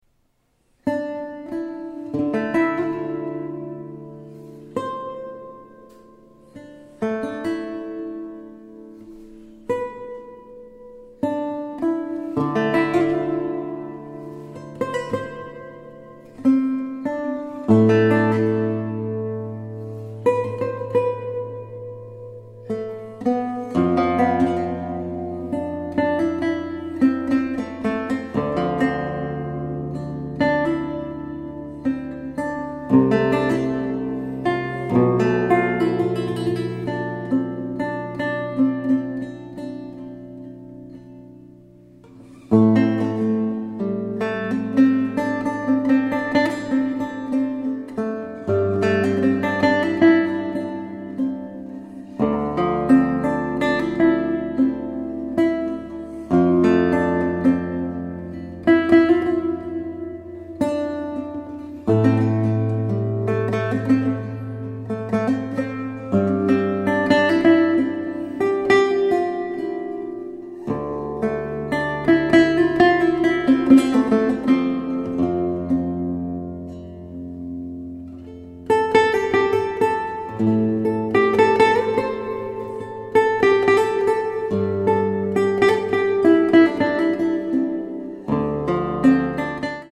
, Lute , Relaxing / Meditative